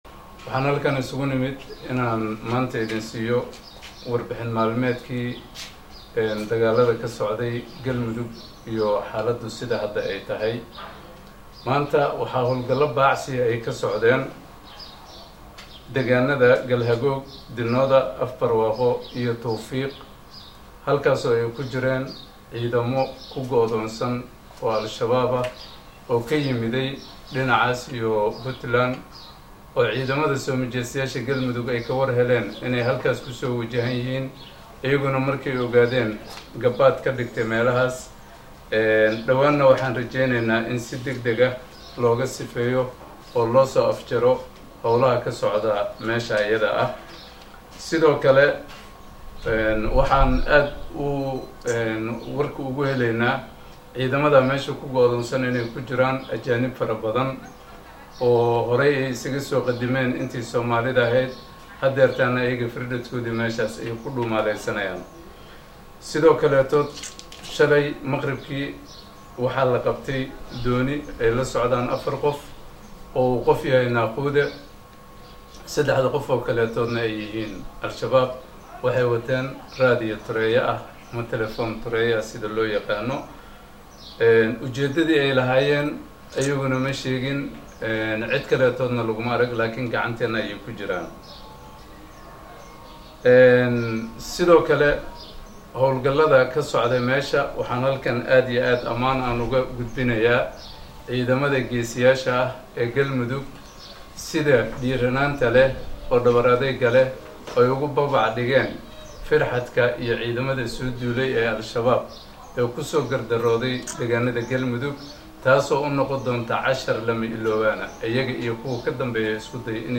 Shir jaraa’id oo uu maanta magaalada Cadaado ku qabtay wasiirka warfaafinta dawlad goboleedka Galmudug Maxamuud Aaden Cusmaan ayuu ku sheegay in dagaalladii maanta ay kula galeen kooxda Shabaab deegaanada Galhagiig, Dinooda, iyo Tawfiiq ee bariga gobolka Mudug ay jab iyo qasaaro wayn gaarsiiyeen kooxda Shabaab ee isku dayday inay amnigooda qalqal galiyaan.
SHIRKA-JARAAID-EE-WASIIRKA-WARFAAFINTA-GALMUDUG-MAXAMUUD-ADEN-CUSMAN.mp3